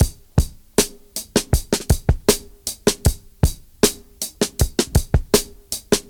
79 Bpm Drum Loop C Key.wav
Free drum loop sample - kick tuned to the C note.
.WAV .MP3 .OGG 0:00 / 0:06 Type Wav Duration 0:06 Size 1,03 MB Samplerate 44100 Hz Bitdepth 16 Channels Stereo Free drum loop sample - kick tuned to the C note.
79-bpm-drum-loop-c-key-oJ0.ogg